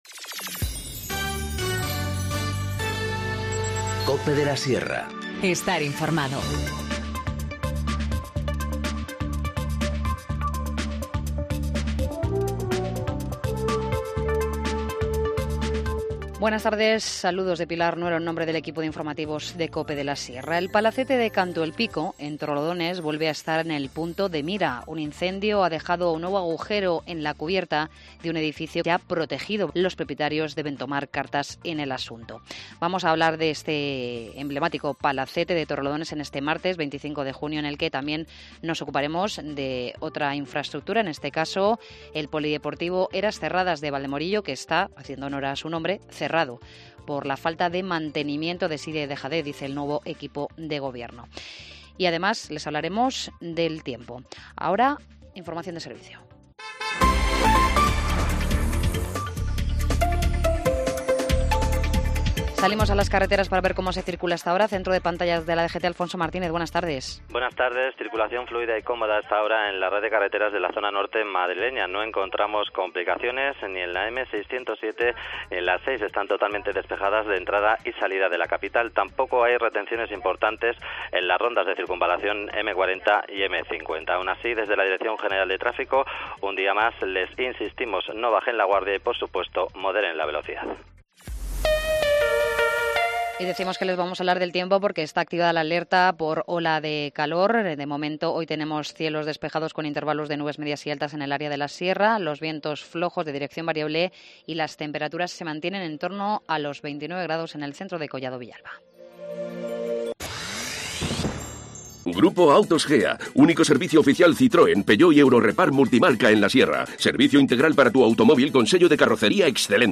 Informativo Mediodía 25 junio 14:20h